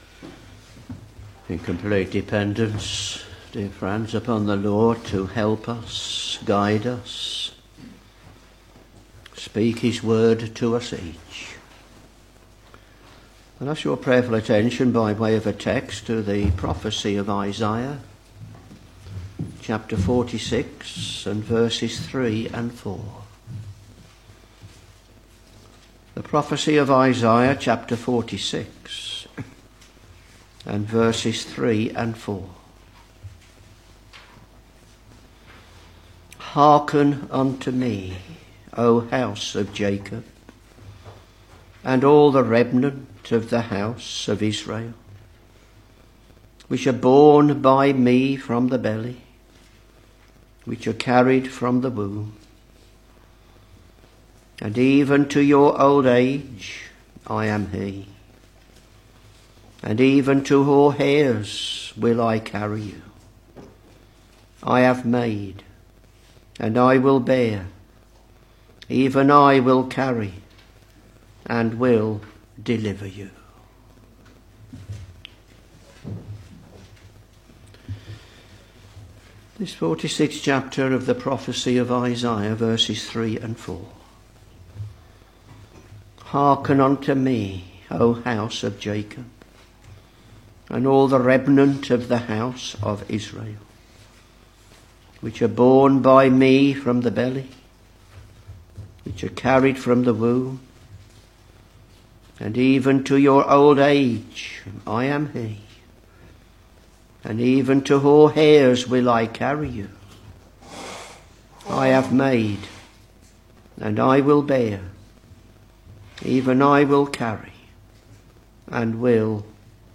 Sermons Isaiah Ch.46 v.3 & v.4